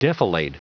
Prononciation du mot defilade en anglais (fichier audio)
Prononciation du mot : defilade